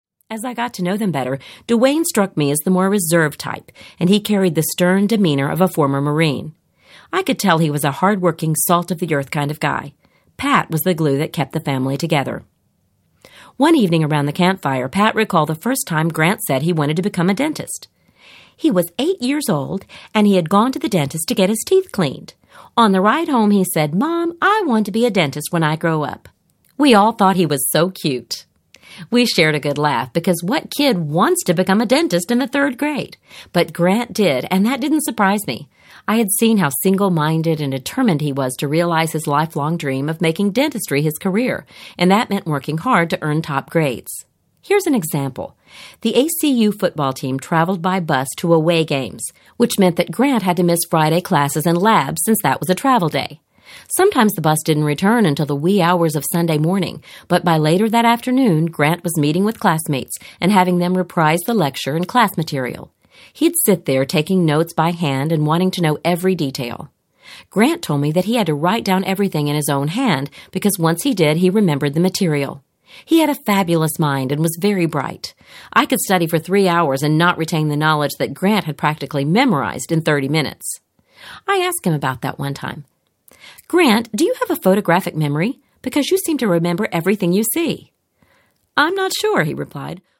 After the Cheering Stops Audiobook
Narrator